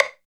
62 HI STIK-L.wav